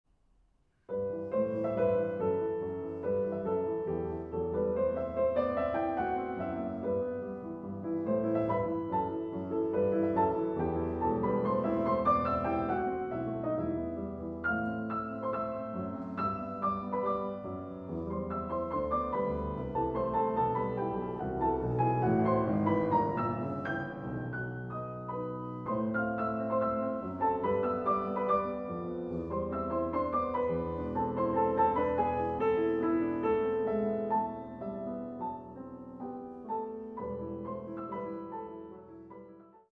arr. for piano 4 hands